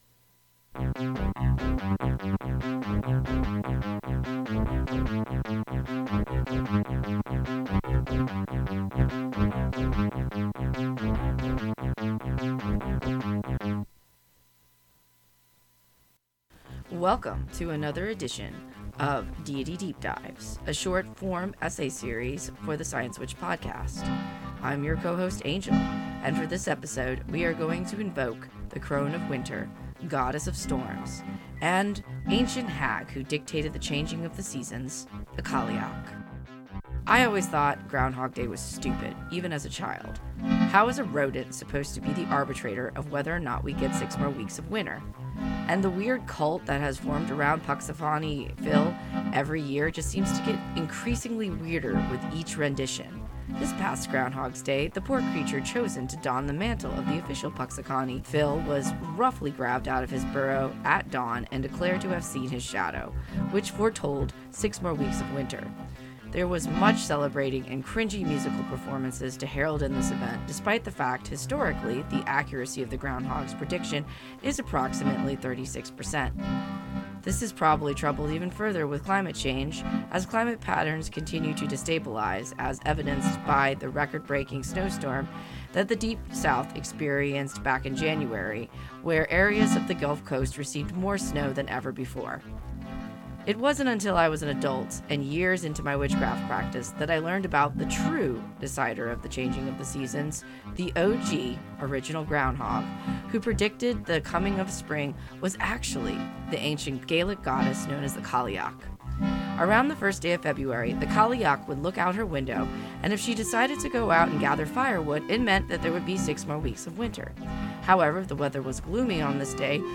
Original music
Cailleach-music.mp3